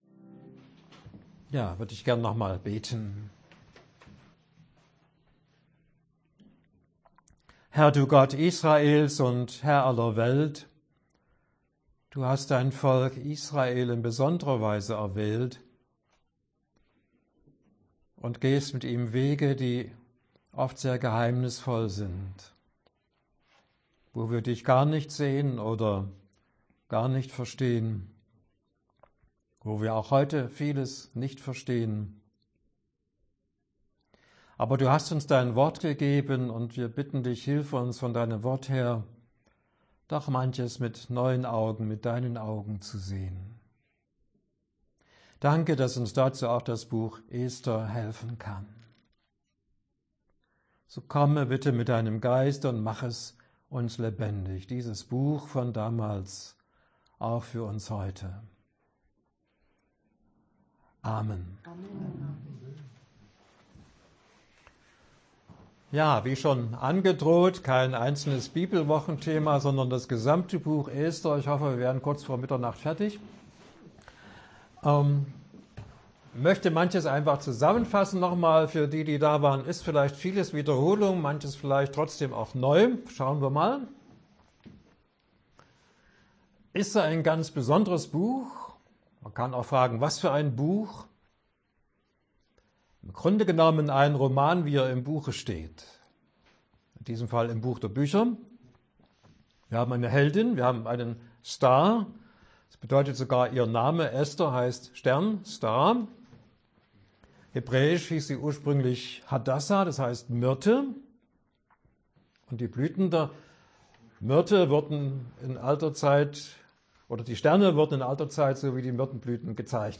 Länge des Vortrages: 58:30 Minuten